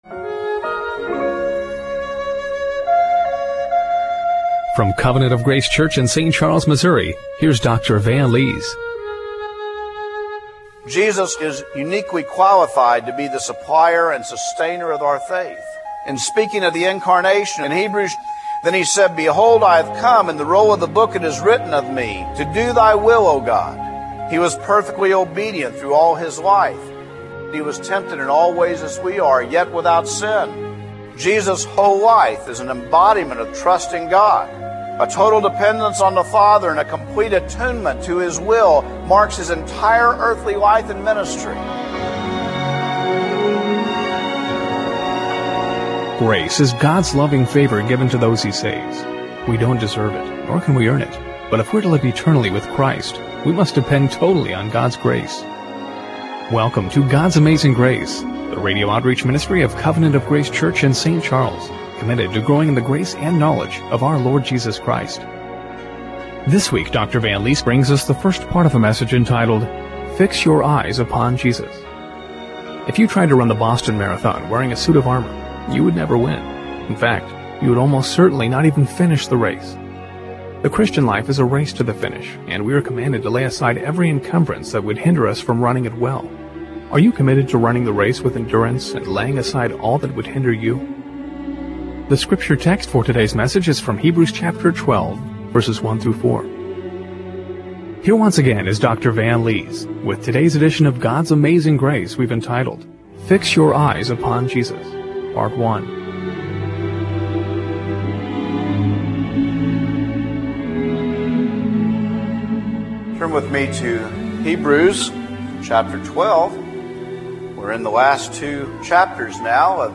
Hebrews 12:1-4 Service Type: Radio Broadcast Are you committed to running the race with endurance and laying aside all that would hinder you?